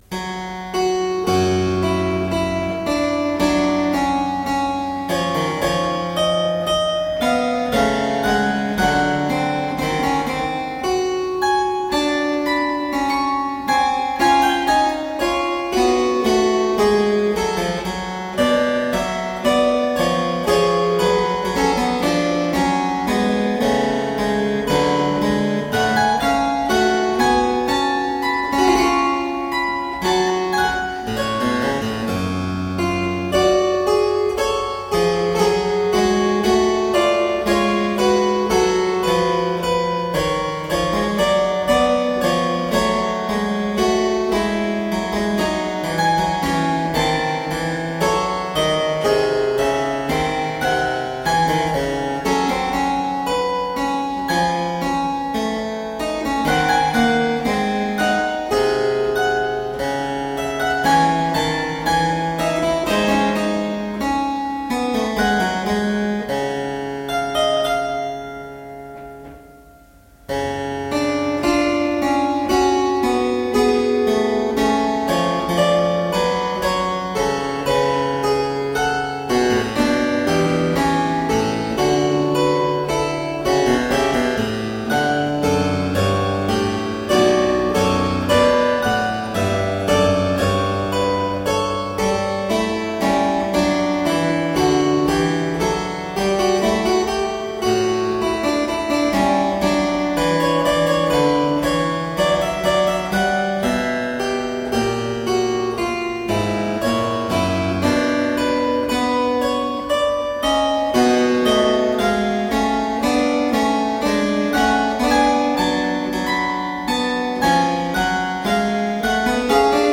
Solo harpsichord music.
Classical, Baroque, Instrumental, Harpsichord